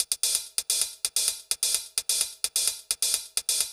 TEC Beat - Mix 13.wav